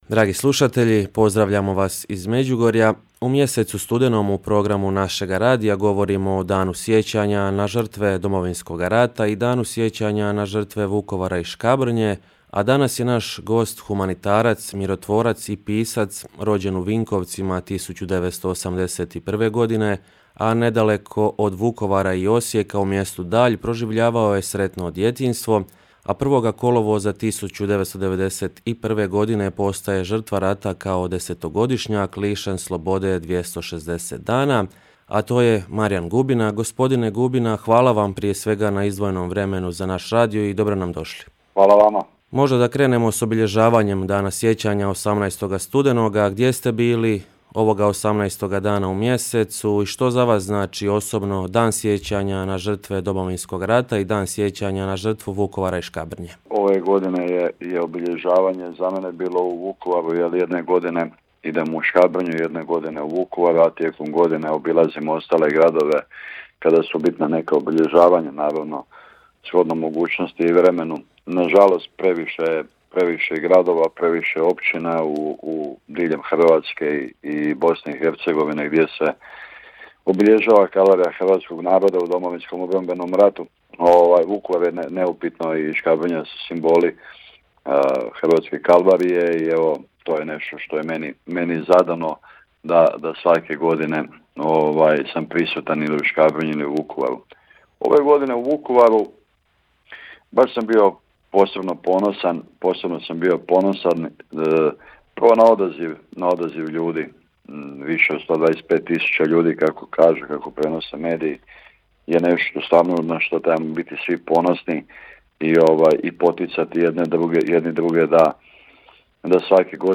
U mjesecu studenom četvrtkom od 10 sati i 15 minuta u programu našega radija emitirali smo razgovore sa sugovornicima o Danu sjećanja na žrtve Domovinskog rata i Danu sjećanja na žrtvu Vukovara i Škabrnje.